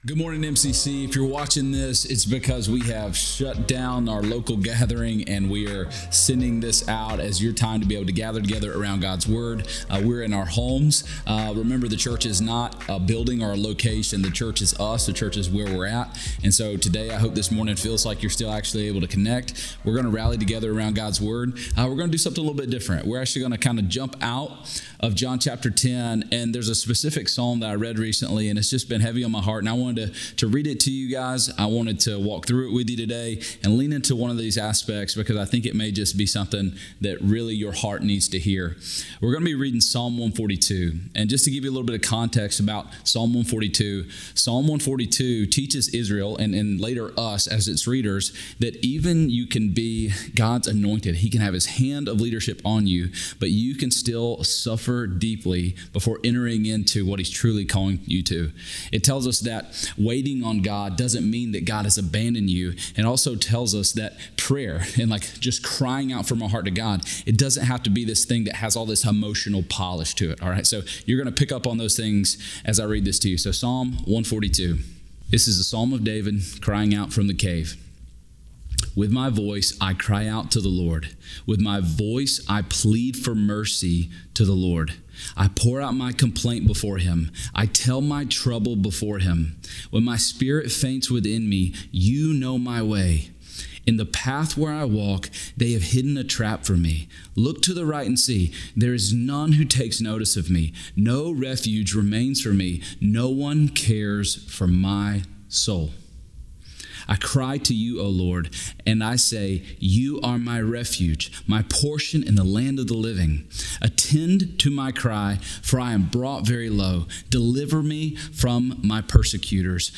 Soul-Care-Sermon.m4a